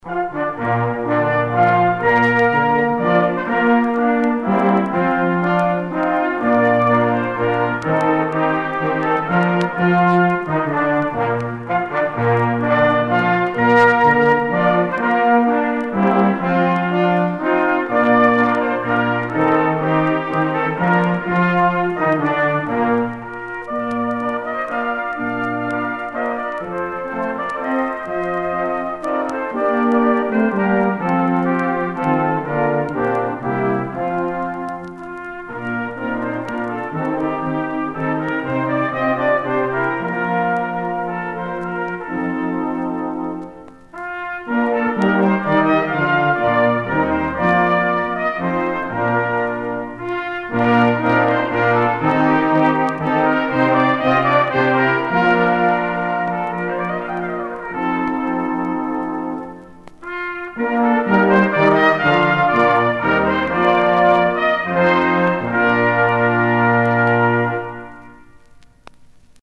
Our music was from traditional American folk, blues and we started the evening off with our smoking announcement.
We then played this delightful waltz version, and the cast stood rock still at attention.